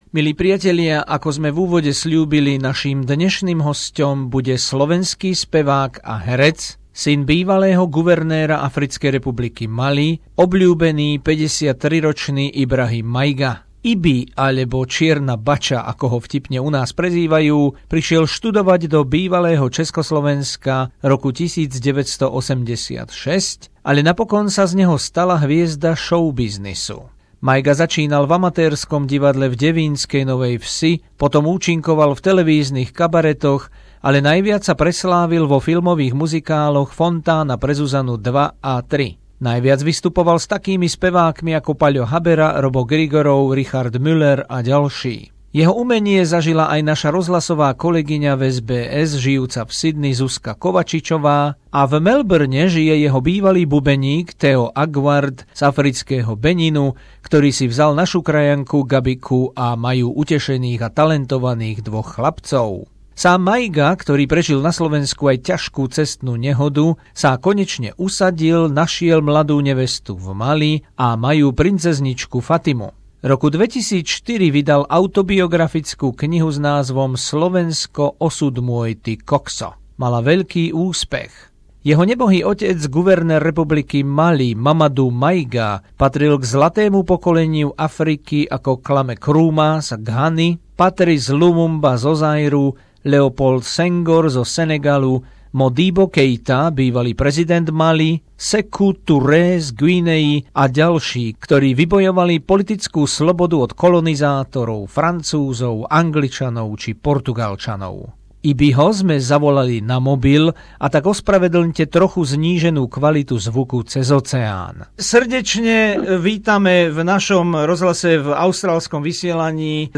Exkluzívny rozhovor so slovenským spevákom a zabávačom Ibrahimom Maigom, synom bývalého guvernéra Mali Mamadou Maigu, o živote, o kríze morálky, o utečencoch, hudbe a láske k Slovensku